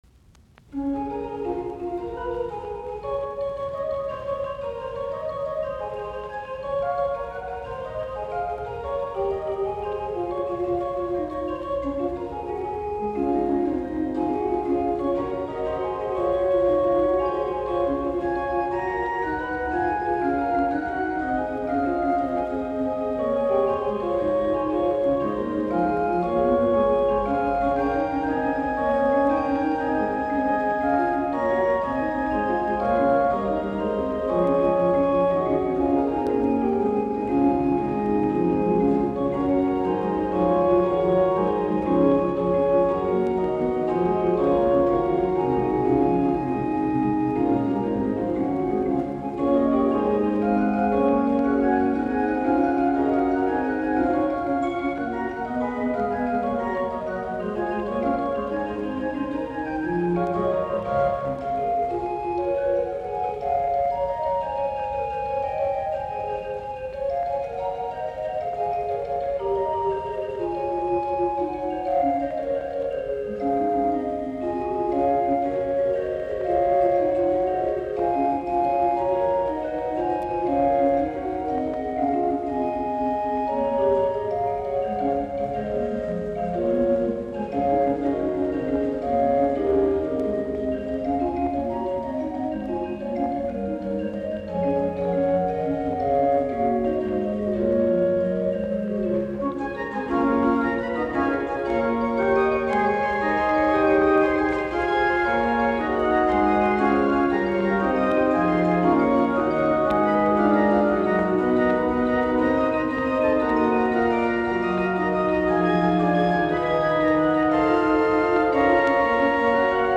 Soitinnus: Urut.
Drottningholm, Drottningholm Castle Chapel.